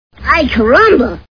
The Simpsons [Bart] Cartoon TV Show Sound Bites